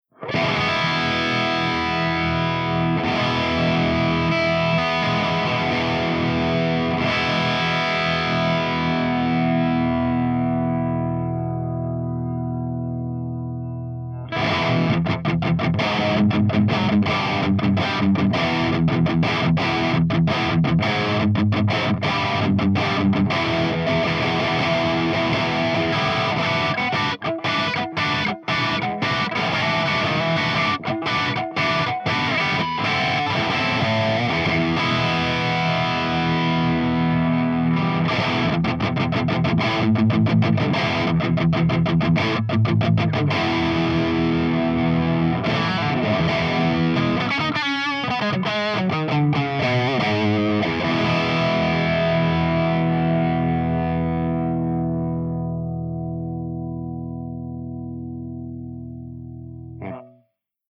160_EVH5150_CH2HIGHGAIN_V30_HB